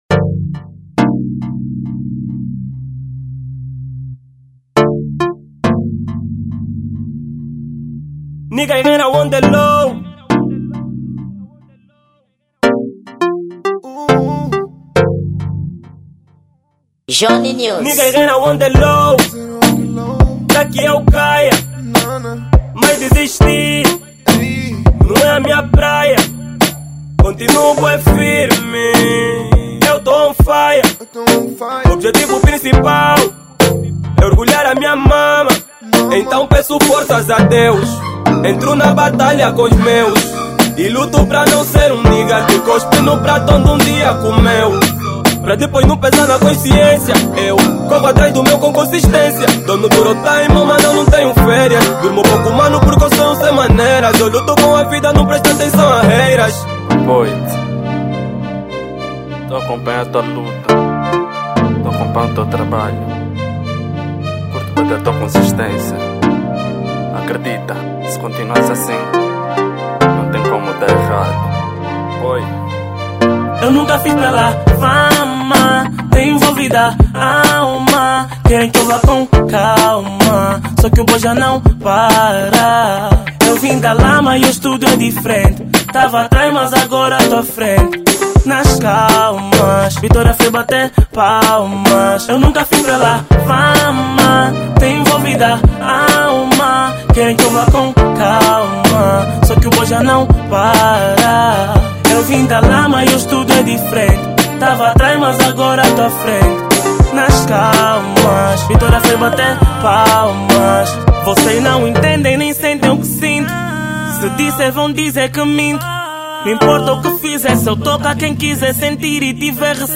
Gênero: Afro Pop